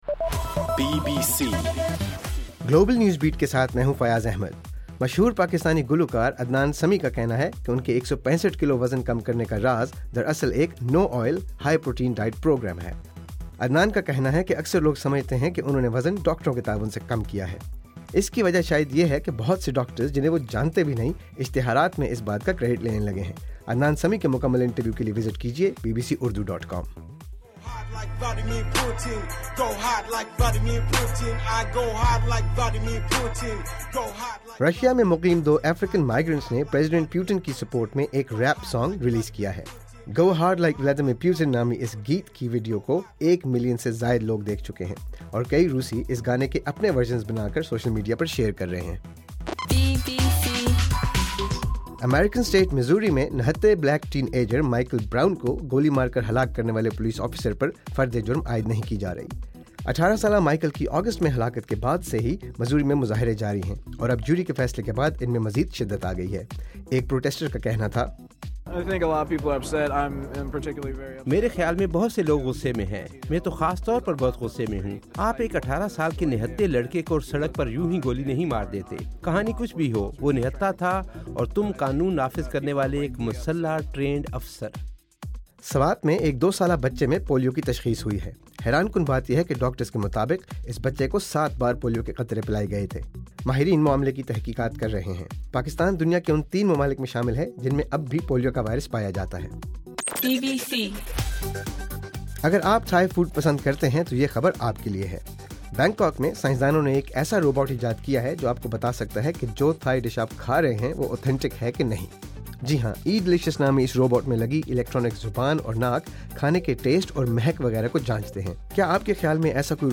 نومبر 25: رات 12 بجے کا گلوبل نیوز بیٹ بُلیٹن